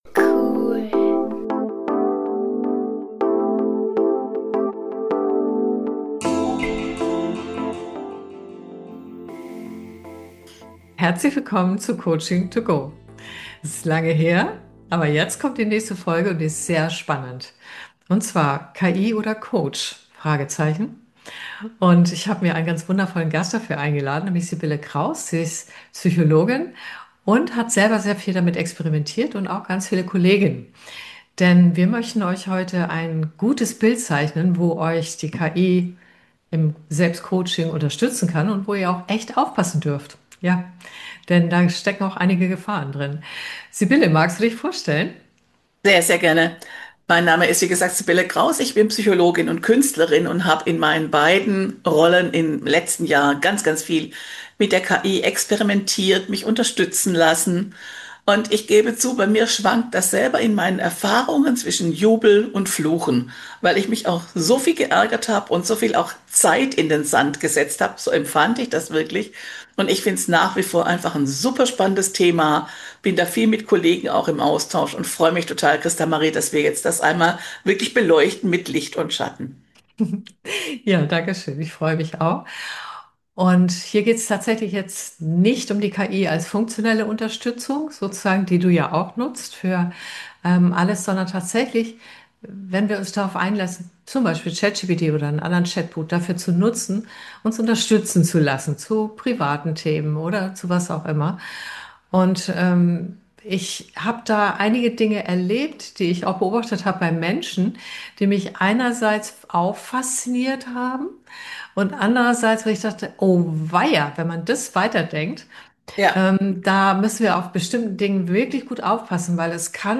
Nicht mit langweiligen Rückblicken, sondern mit Überraschungsgästen und Themen, die so bunt sind, wie der Podcast selbst.
Weiter gehts mit der Beantwortung von Fragen von Hörer*Innen zu Selbstcoaching, Führung, Umgang mit Künstlicher Intelligenz (KI), Buchtips und Energiearbeit. Außerdem werden im Podcast noch Experimente live vor laufendem Mikrophon gemacht.